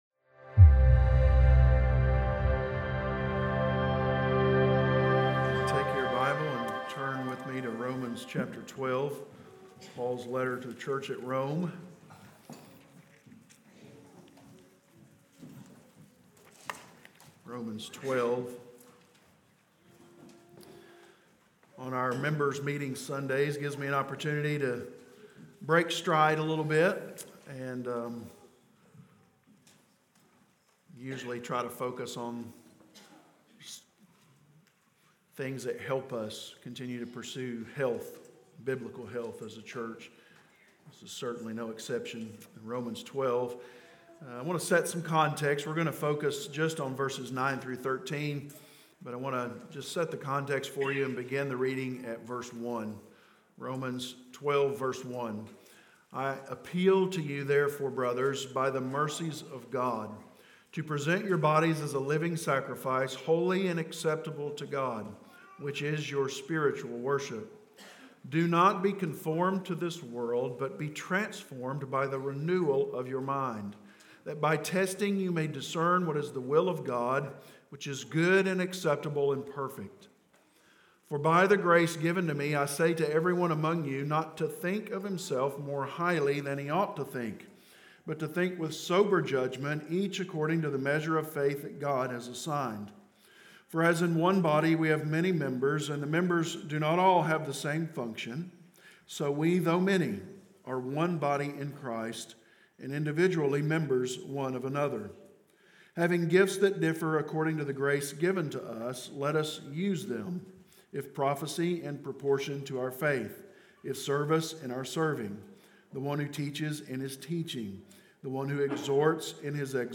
Sermon Series - Corydon Baptist Church - A Christian Family of Disciple Making Disciples